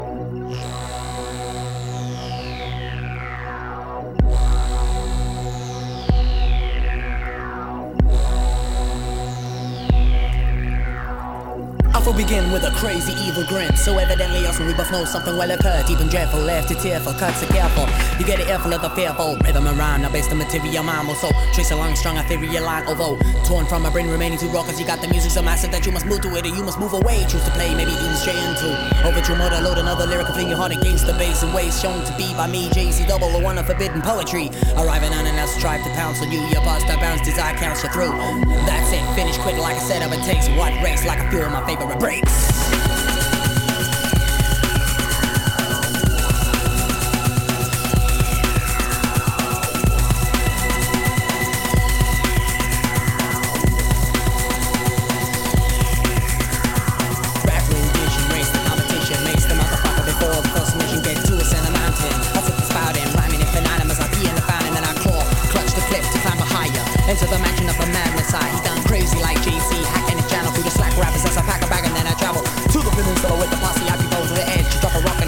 少しチャラついた雰囲気と、サイケさが混ざりあったUK Hip Hop/Breaks。